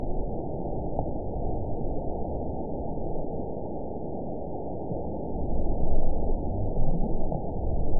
event 918072 date 04/29/23 time 13:37:11 GMT (2 years ago) score 7.08 location TSS-AB03 detected by nrw target species NRW annotations +NRW Spectrogram: Frequency (kHz) vs. Time (s) audio not available .wav